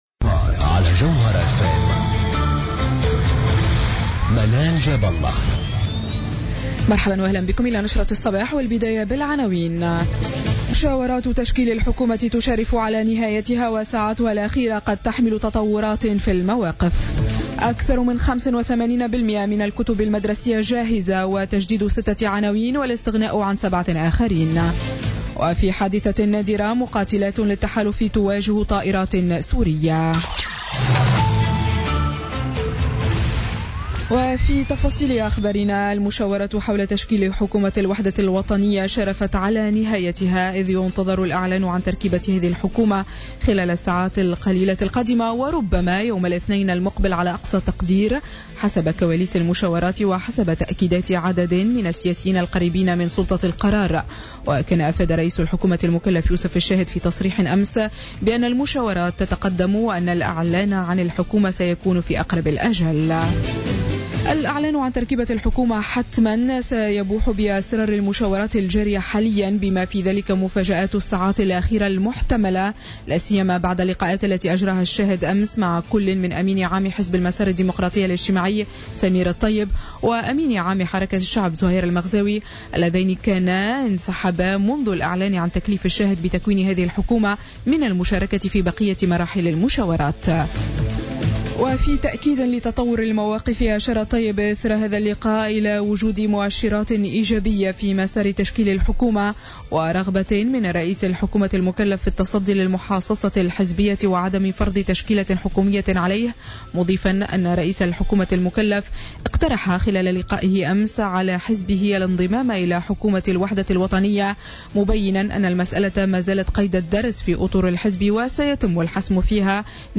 نشرة أخبار السابعة صباحا ليوم السبت 20 أوت 2016